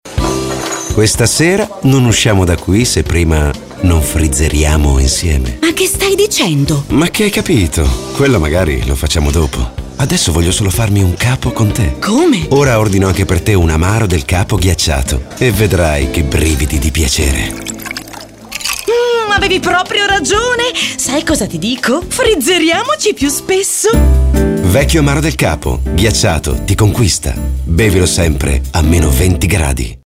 voce calda, dinamica con spiccate doti interpretative
Sprechprobe: Werbung (Muttersprache):